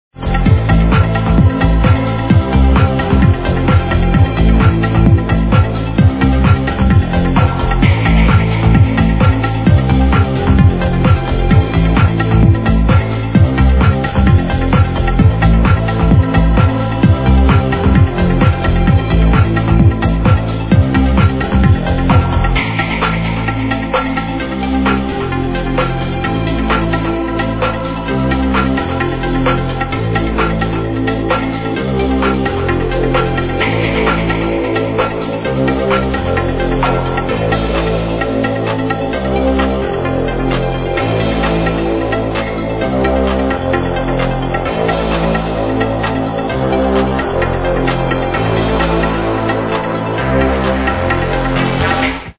I ve heard the samular trance progressive melody